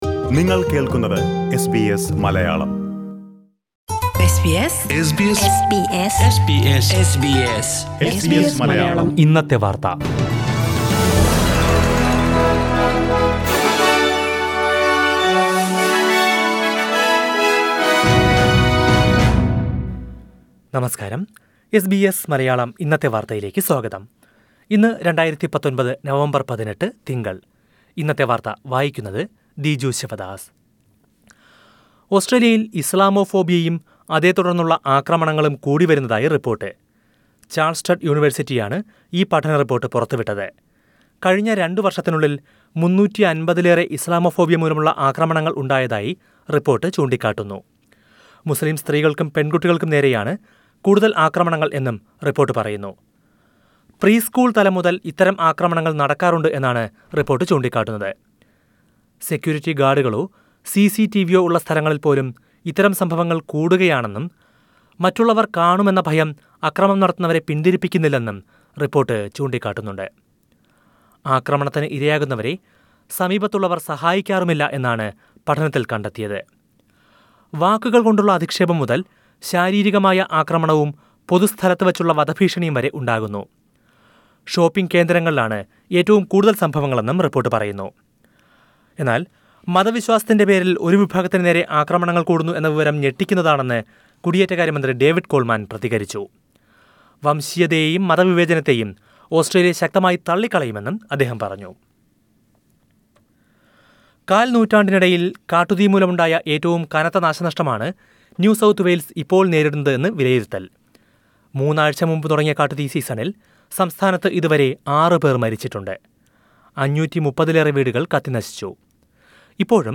2019 നവംബർ 18ലെ ഓസ്ട്രേലിയയിലെ ഏറ്റവും പ്രധാന വാർത്തകൾ കേൾക്കാം…